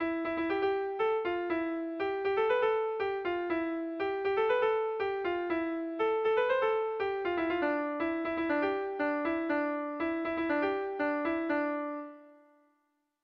Kontakizunezkoa
Amaiur < Baztan < Baztan Bidasoa < Nafarroa < Euskal Herria
ABD